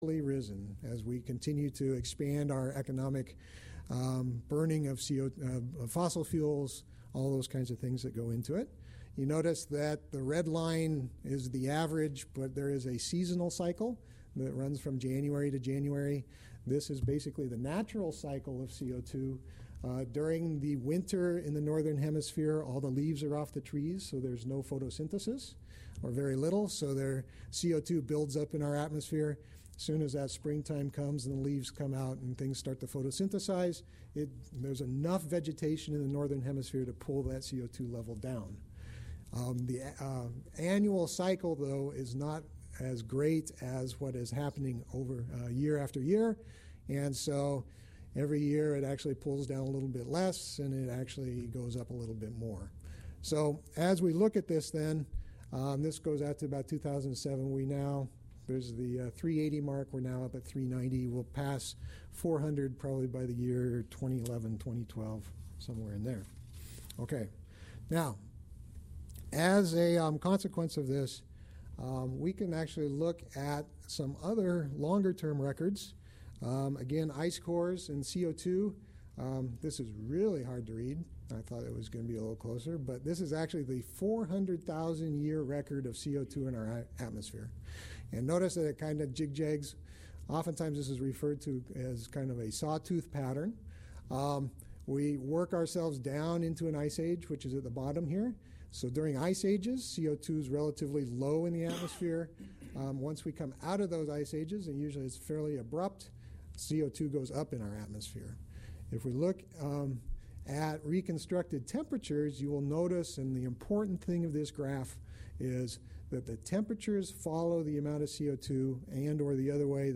MP3: Recording of Stakeholder Meeting